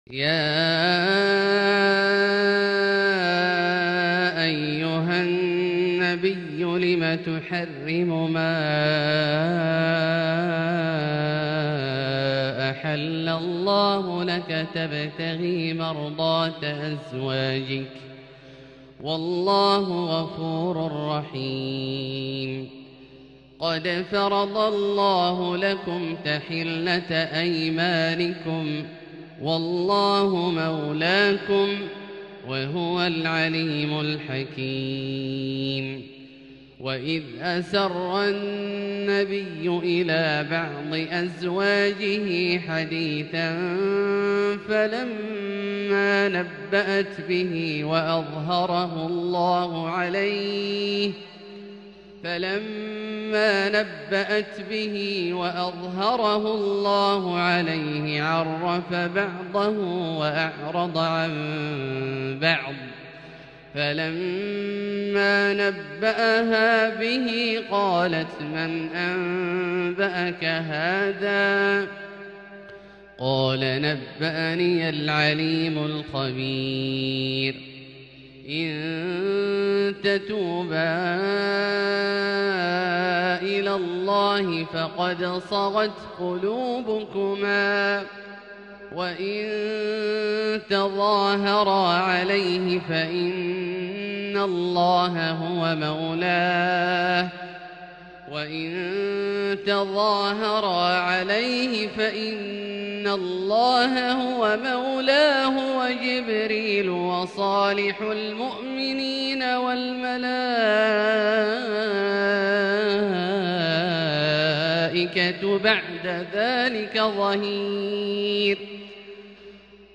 تلاوة تذرف الدمع لـ سورة التحريم كاملة للشيخ أ.د. عبدالله الجهني من المسجد الحرام | Surat At-Tahrim > تصوير مرئي للسور الكاملة من المسجد الحرام 🕋 > المزيد - تلاوات عبدالله الجهني